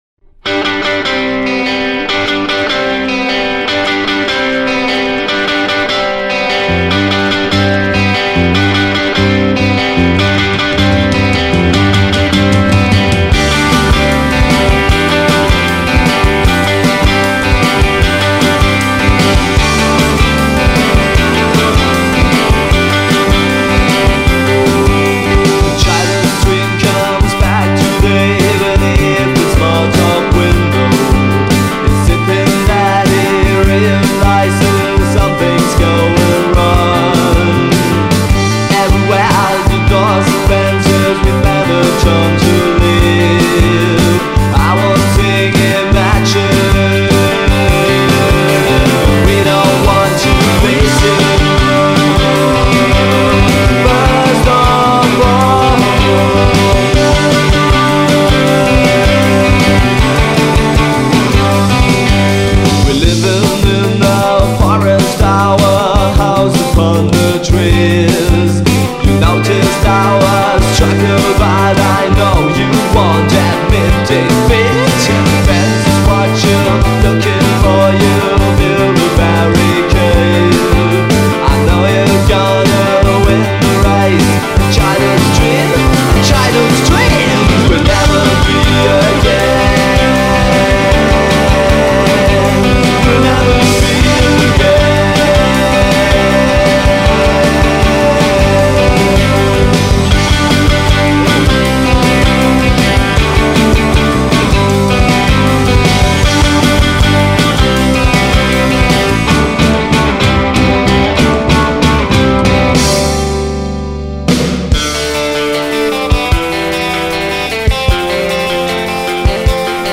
Vinyl single recorded 1992 at deadeye studio